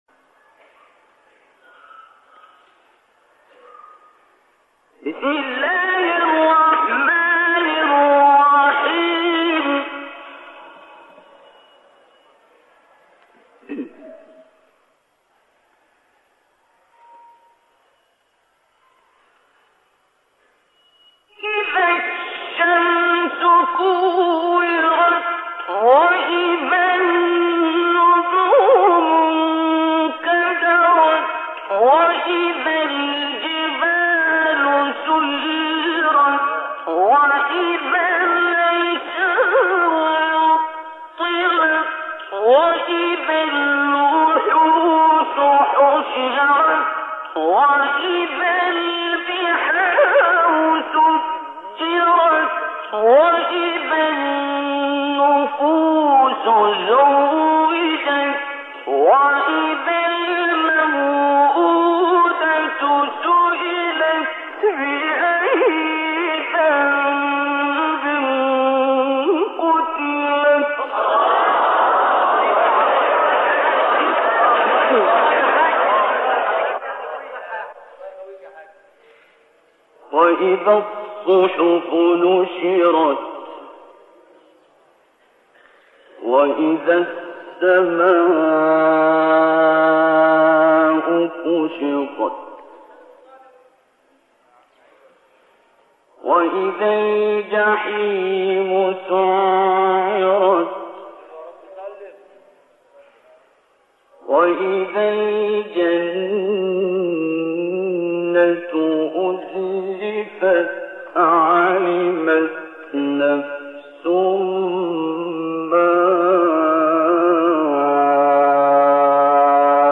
تلات سوزناک سوره تکویر استاد عبدالباسط | نغمات قرآن | دانلود تلاوت قرآن